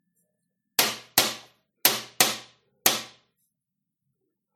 Wyklaskiwanie ciągu wartości rytmicznych